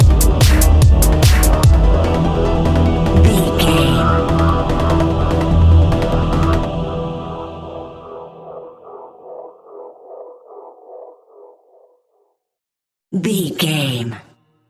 Trance Choir Stinger.
Aeolian/Minor
Fast
meditative
hypnotic
epic
dark
drum machine
synthesiser
uptempo
synth leads
synth bass